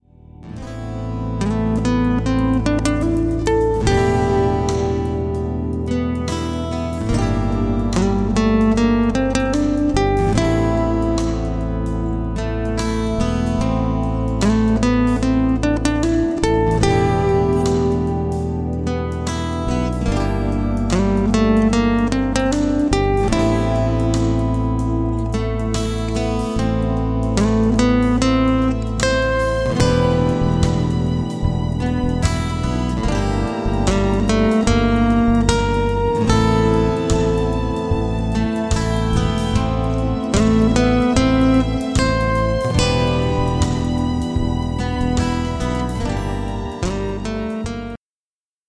Genre: Meditation